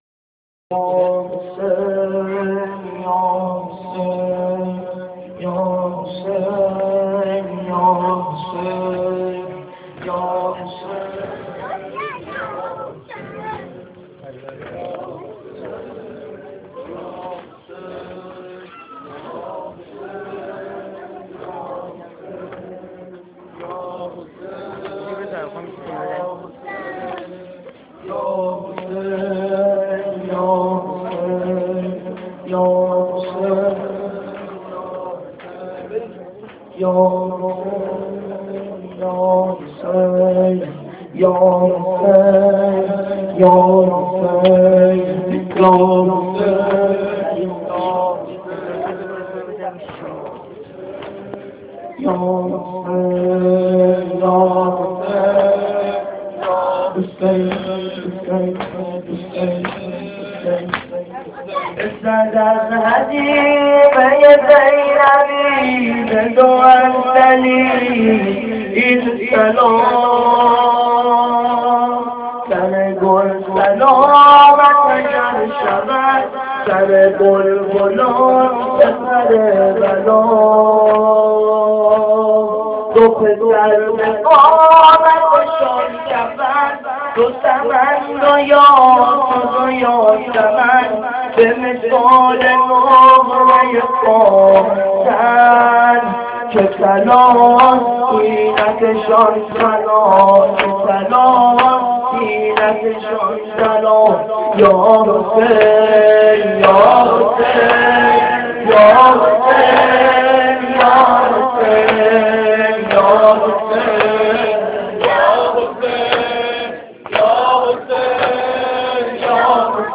سینه زنی4.amr
سینه-زنی4.amr